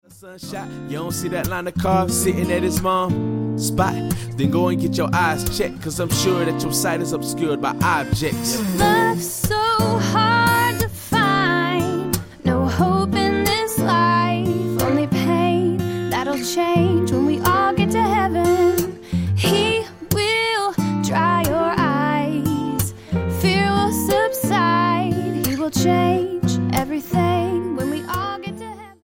STYLE: Hip-Hop
Acoustic Version